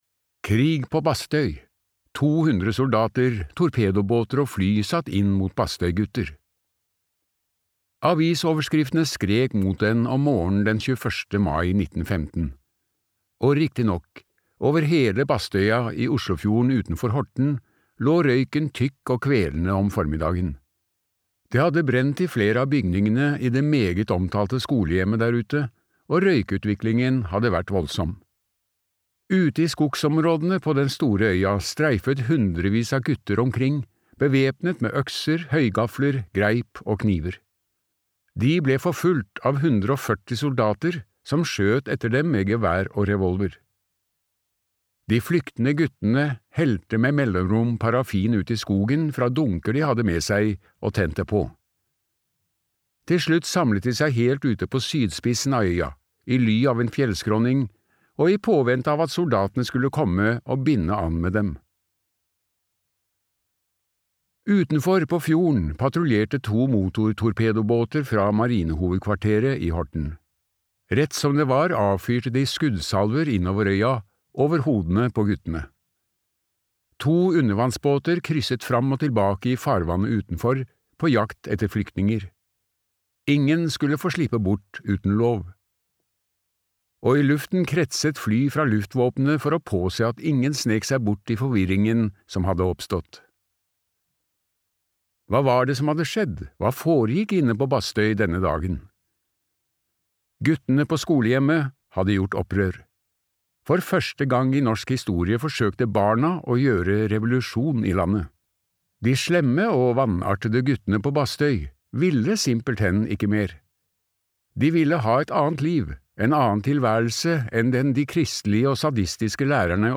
Djeveløya i oslofjorden - historien om Bastøy og andre straffeanstalter for slemme gutter (lydbok) av Yngvar Ustvedt